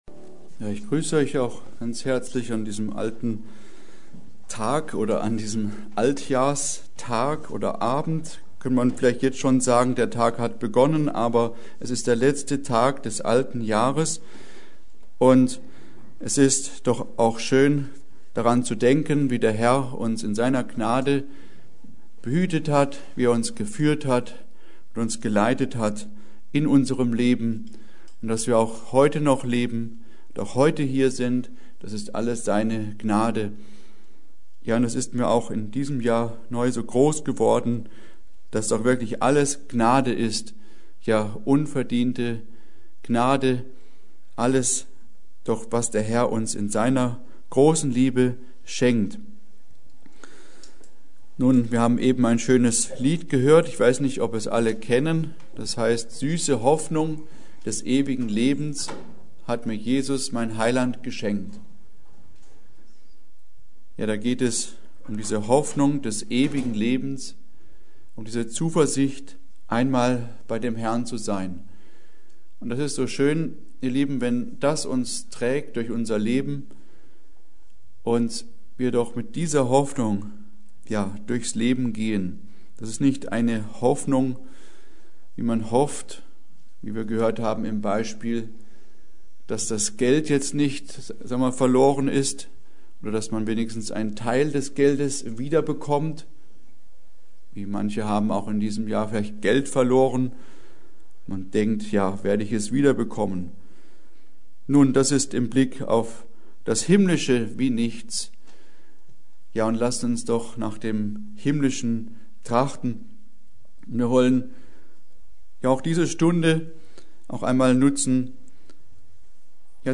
Predigt: Rückblick auf das eigene Leben im vergangenen Jahr